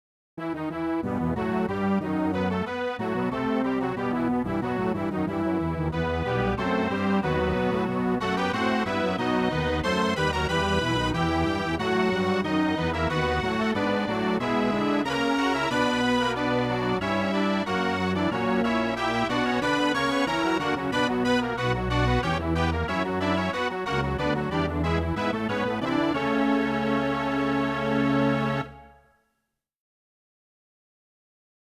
It's a SNES/polyphonic sort of track (not sure how else to describe it), since I like that timbre.